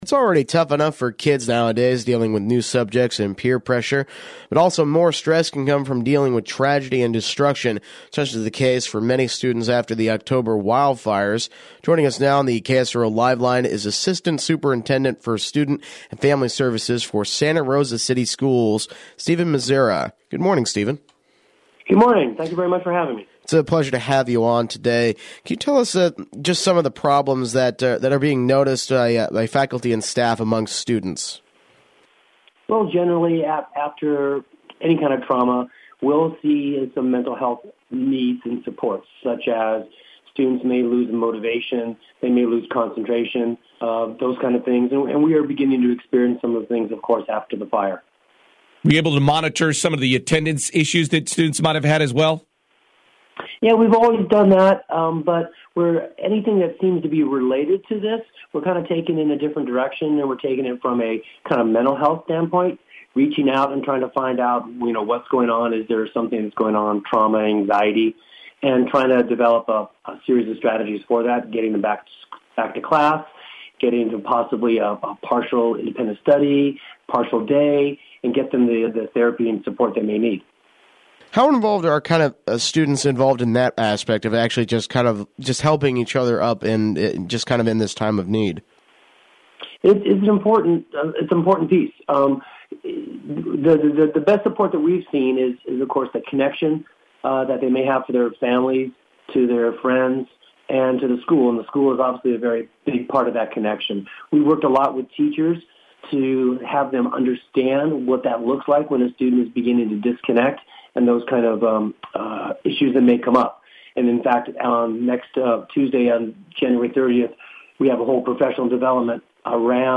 Interview: Schools Start to See Negative Impact on Academic and Attendance Post Wildfire